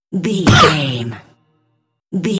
Electronic stab hit trailer
Sound Effects
Atonal
heavy
intense
dark
aggressive